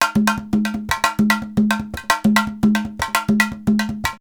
PERC 26.AI.wav